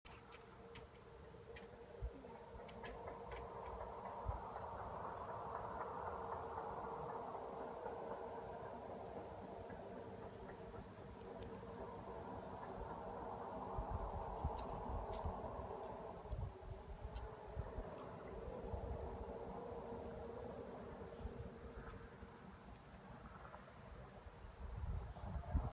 The following audio was taken from over 2000 feet away from the Manthei gravel pit.  The audio was not increased or changed in any way and taken with an iPhone on 8/28/2017 at 10:12 AM.  Does this sound like rain drops?